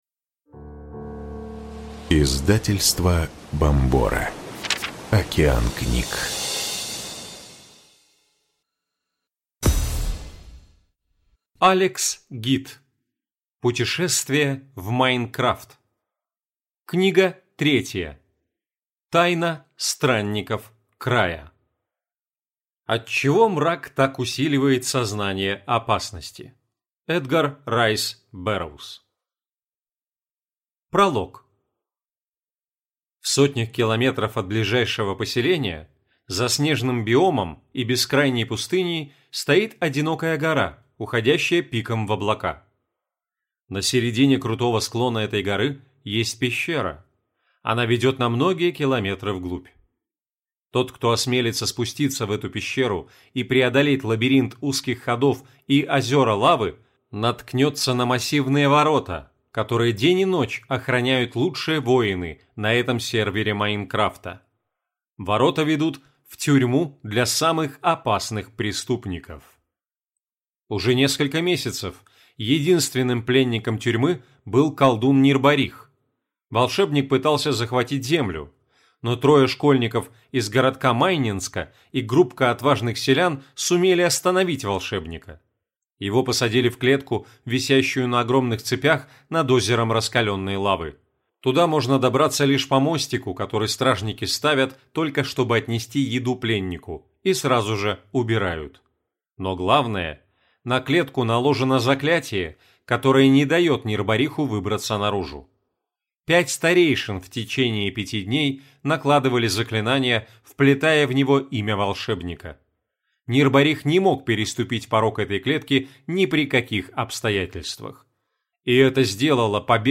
Аудиокнига Тайна странников Края | Библиотека аудиокниг